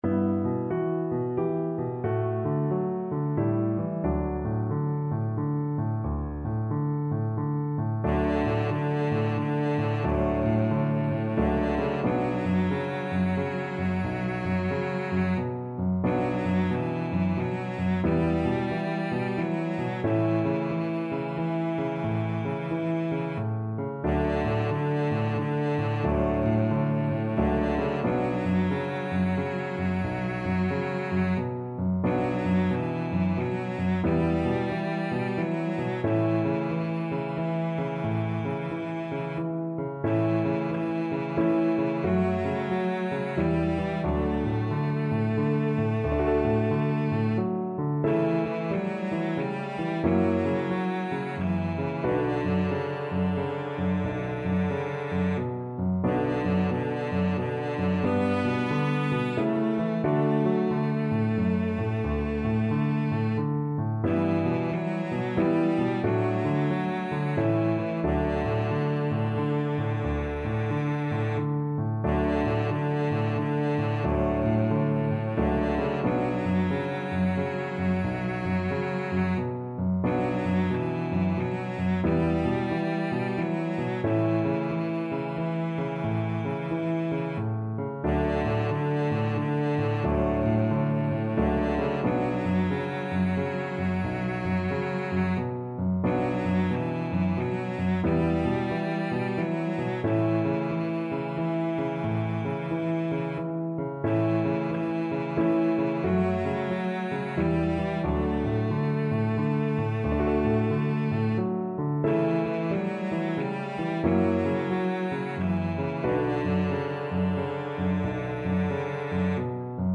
Cello version
Cello
D major (Sounding Pitch) (View more D major Music for Cello )
=90 Andante, gentle swing
3/4 (View more 3/4 Music)
Traditional (View more Traditional Cello Music)